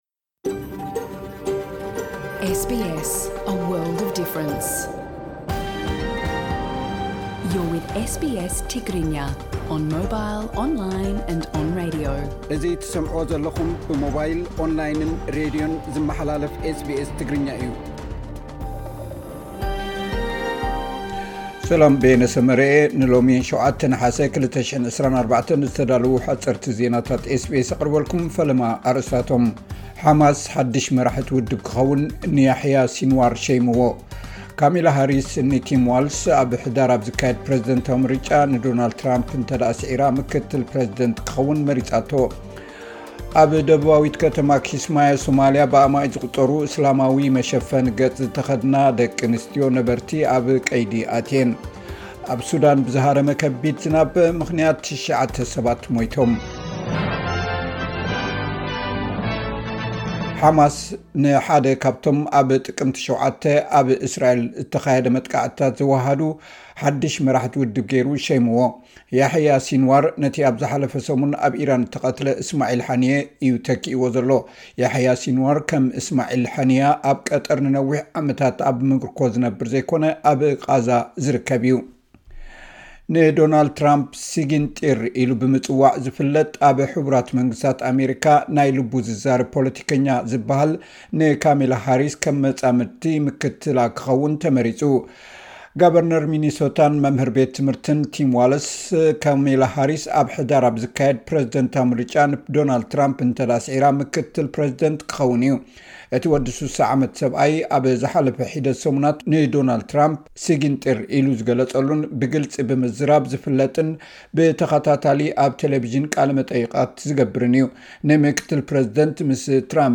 ሓጸርቲ ዜናታት ኤስ ቢ ኤስ ትግርኛ (07 ነሓሰ 2024)